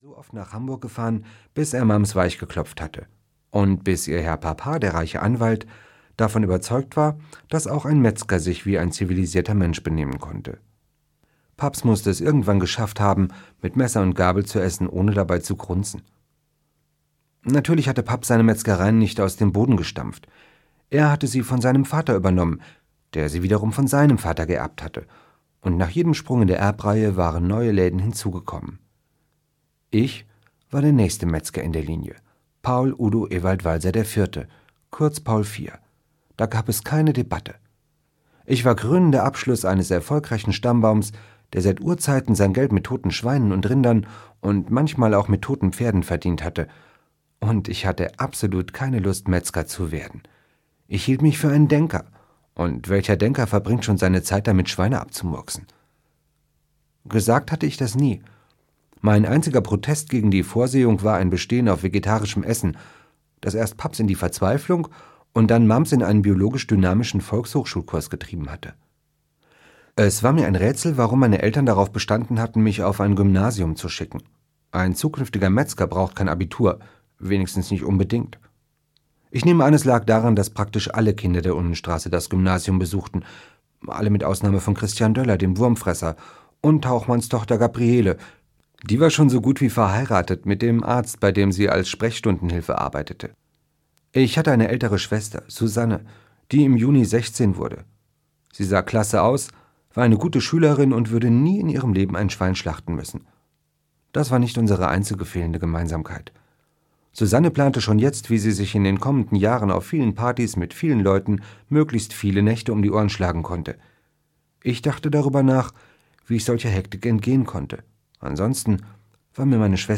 Paul Vier und die Schröders - Andreas Steinhöfel - Hörbuch